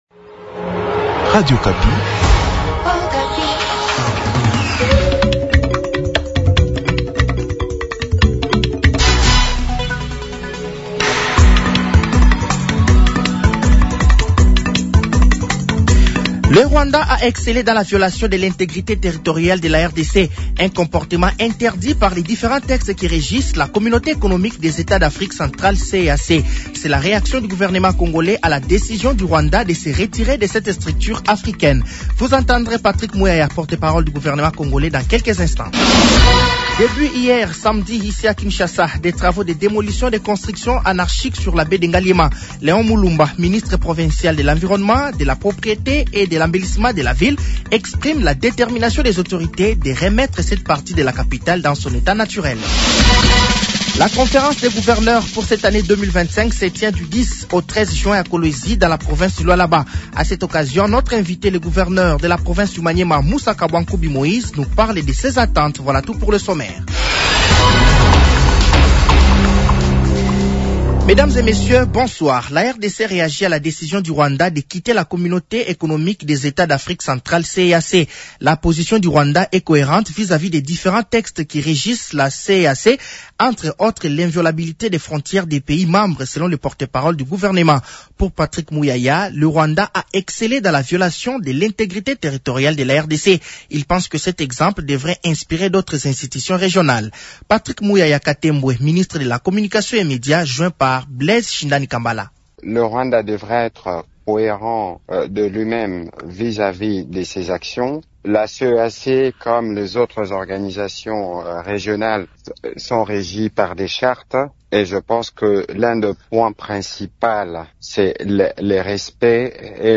Journal français de 18h de ce dimanche 08 juin 2025